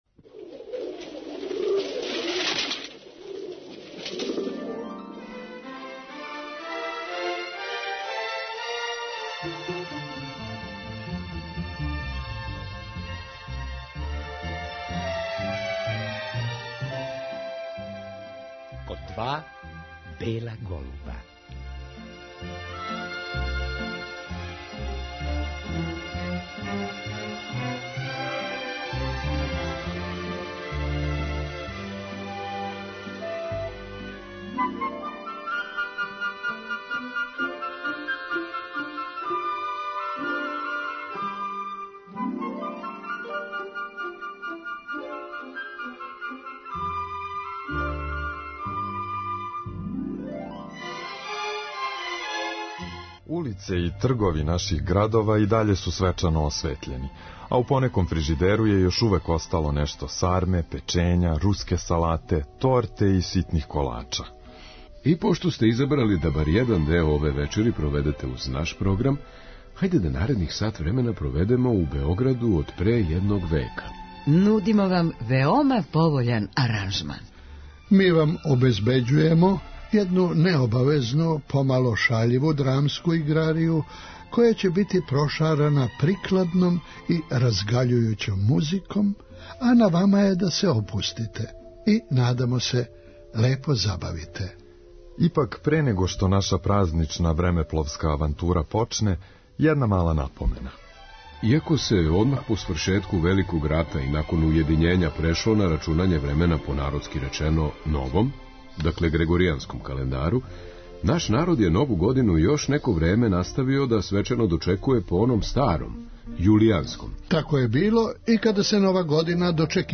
Ми вам обезбеђујемо једну необавезну, помало шаљиву драмску играрију, која ће бити прошарана прикладном и разгаљујућом музиком, а на вама је да се опустите и, надамо се, лепо забавите.